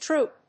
/trúːp(米国英語), tru:p(英国英語)/